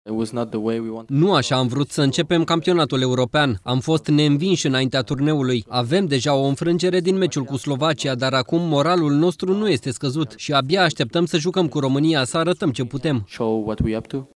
Într-o conferință de presă cu jurnaliștii belgieni, fundașul Arthur Theate a vorbit despre startul echipei sale la EURO, dar și despre perspectivele partidei cu România.
21iun-15-Arthur-Theate-despre-Belgia-Romania-tradus.mp3